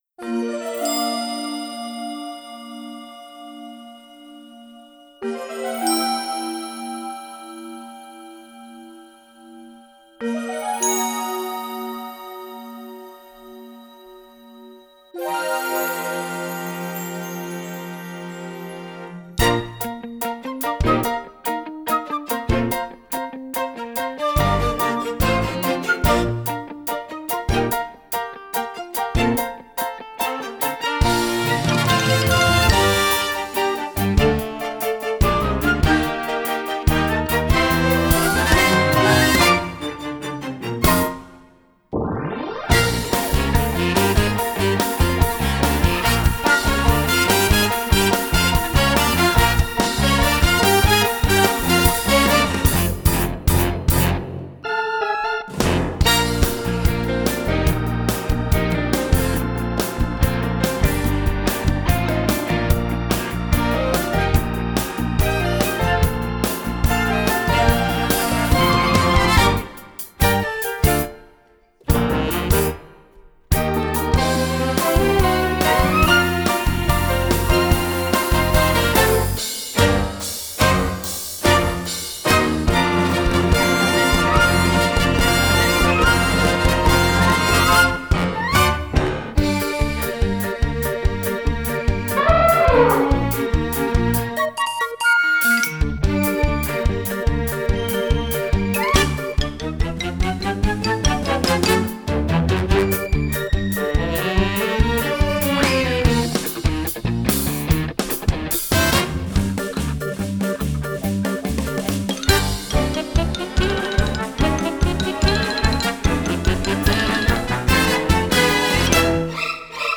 Performance Track